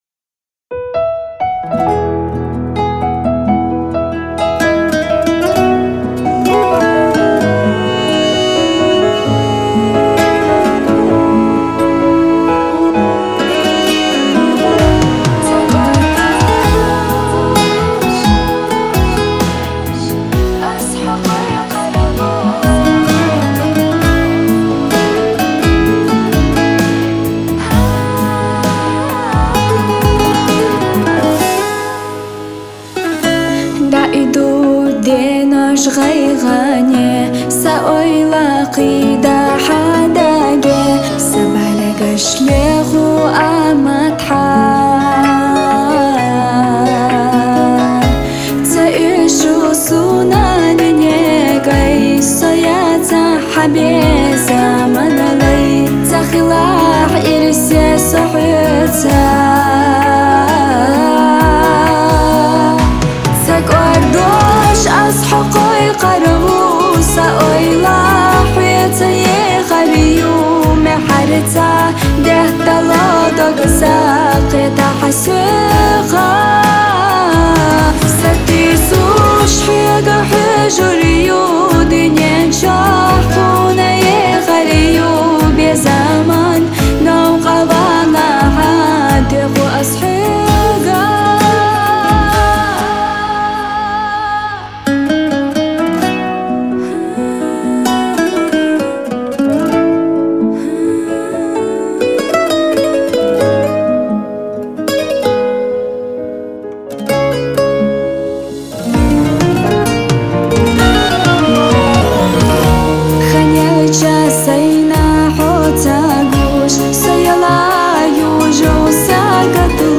Chechen Music